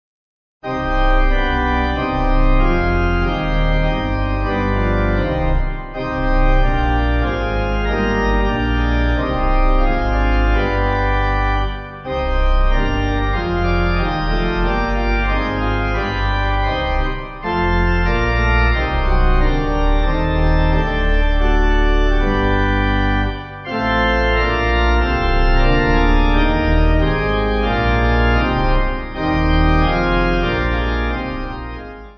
Organ
(CM)   4/Cm